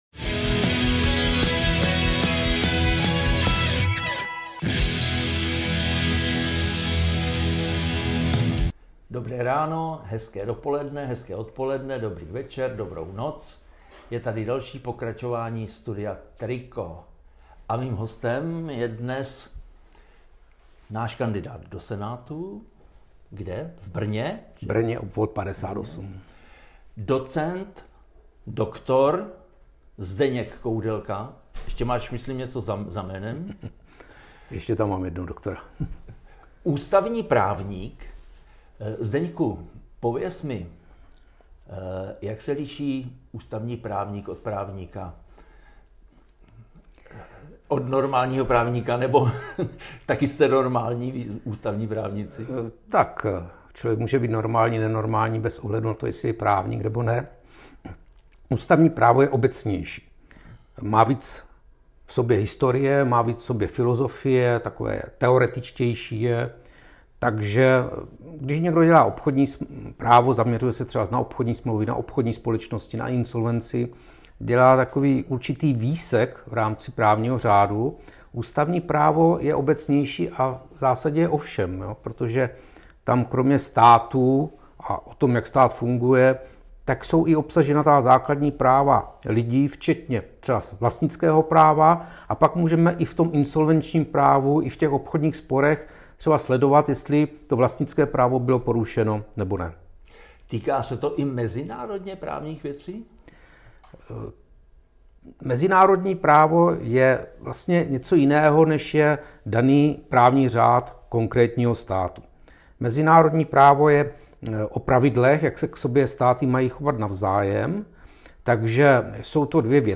Studio TRIKO navštívil doc. JUDr. Zdeněk Koudelka, Ph.D., právní expert Trikolory a kandidát do Senátu.